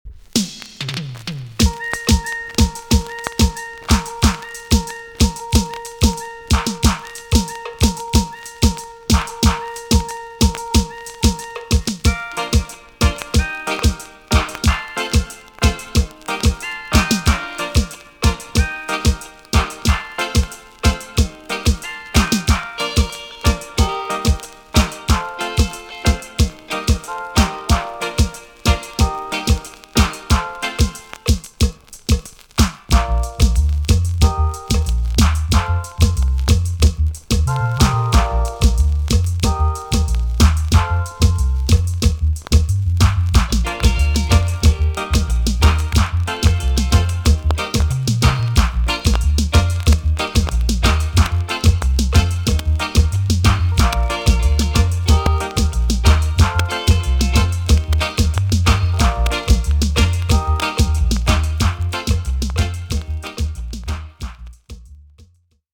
B.SIDE Version
VG+ 少し軽いチリノイズが入ります。